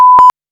Уровень шума